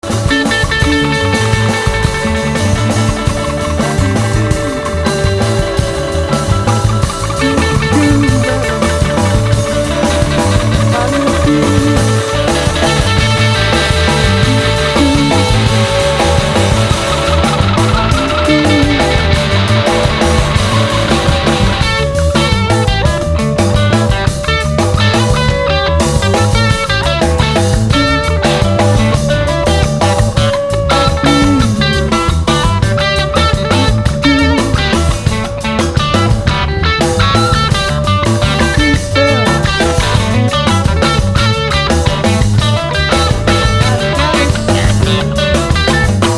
synthesizer and percussion
piano,drums and vocals
guitar, bass and vocals.
is a good eleven-minute trippy number